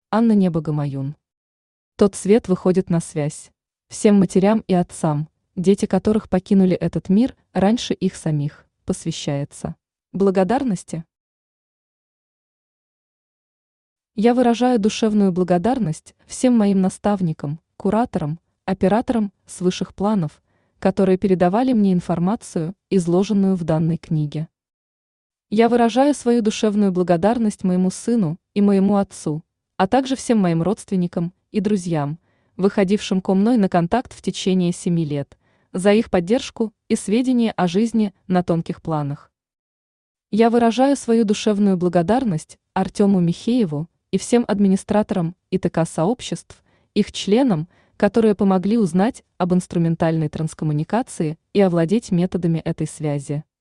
Аудиокнига Тот свет выходит на связь | Библиотека аудиокниг
Aудиокнига Тот свет выходит на связь Автор Анна Небо-Гамаюн Читает аудиокнигу Авточтец ЛитРес.